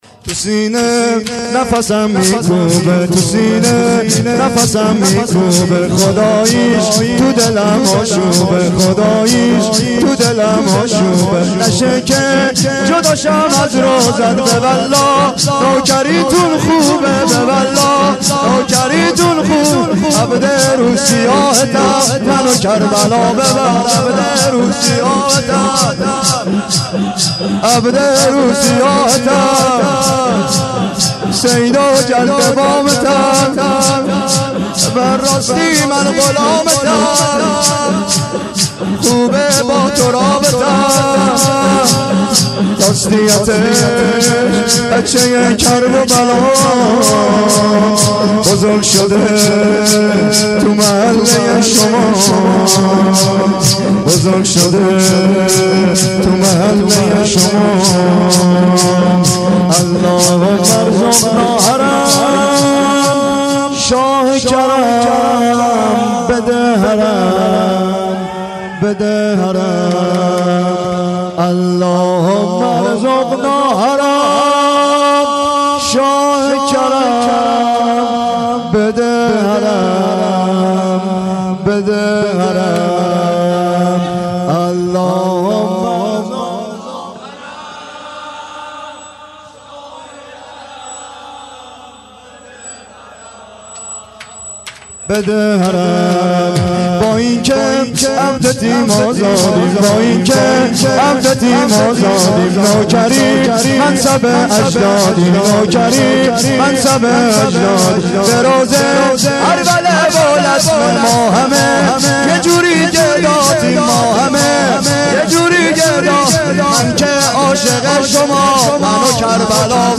تو سینه نفسم میکوبه (سینه زنی/ شور